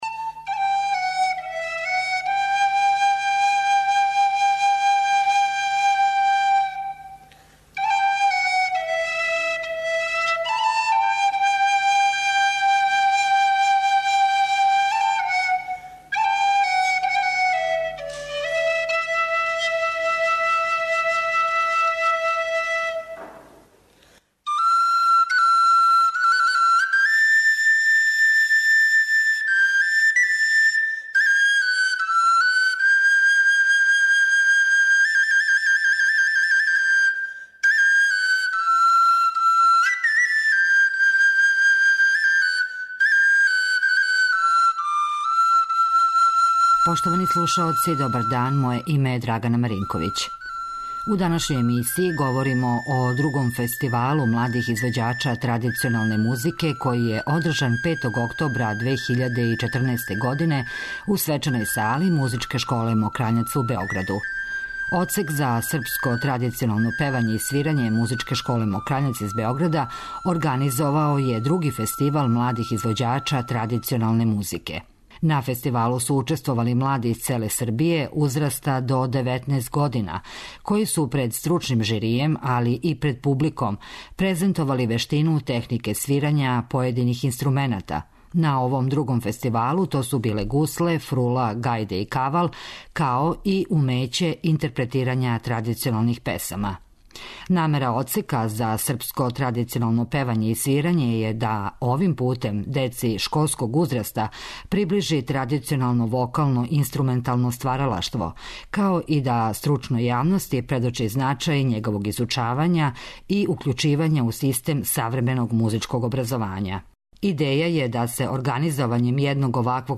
Данас говоримо о другом фестивалу младих извођача традиционалне музике, који је био одржан у музичкој школи 'Мокрањац', у Београду.